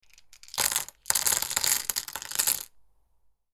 Authentic Mahjong Tiles Shuffling Sound Effect
A mechanical clacking sound of shuffling mahjong tiles delivers a sharp, rhythmic, and clearly defined texture.
The sound features a clean decay that integrates smoothly into game engines.
Authentic-mahjong-tiles-shuffling-sound-effect.mp3